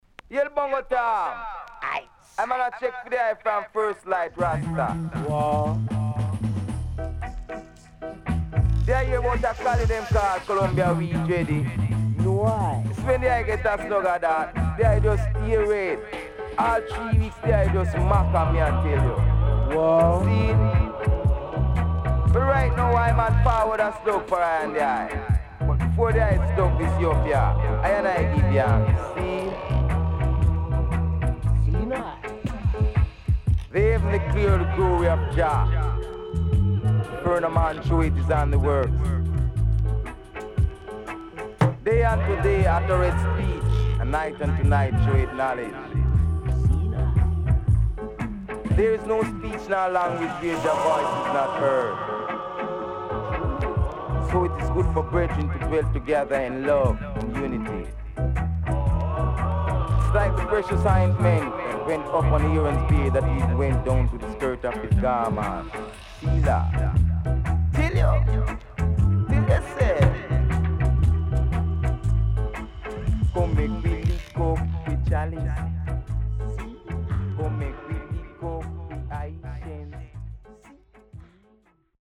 Very Cool & Deep Deejay Album